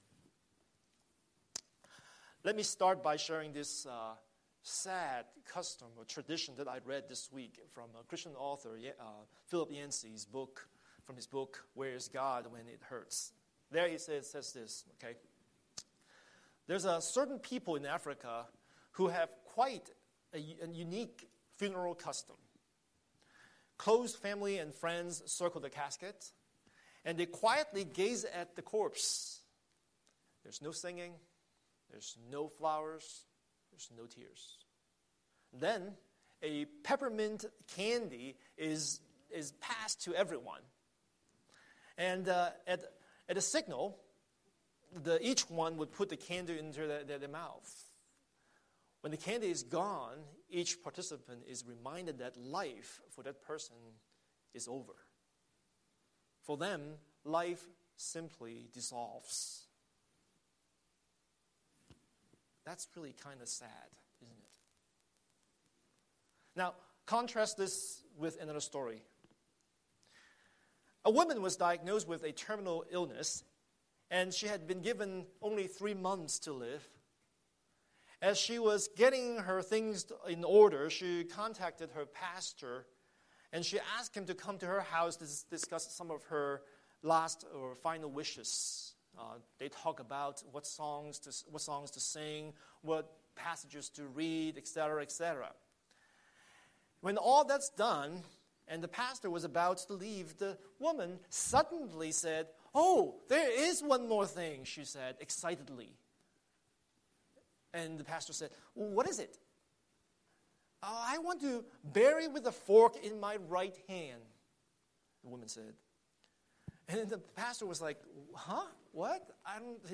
Scripture: 1 Corinthians 15:50-58 Series: Sunday Sermon